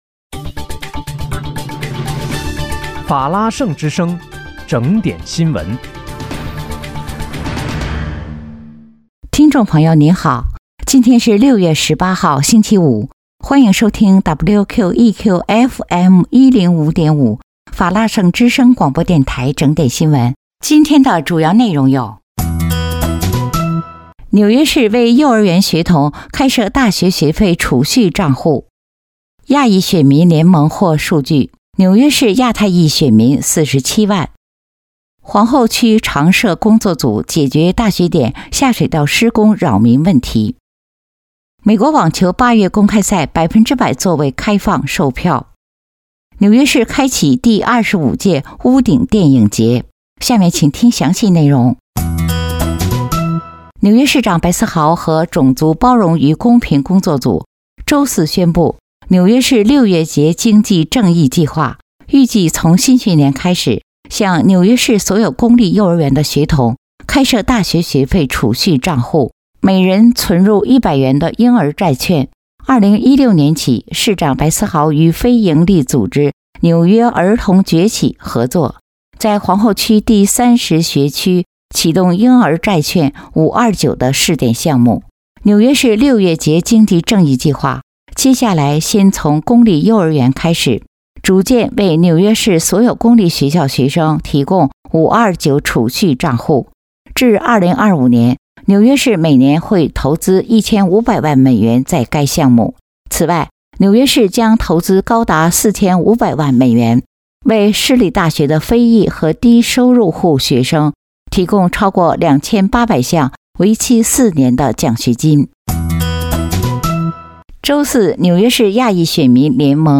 6月18日（星期五）纽约整点新闻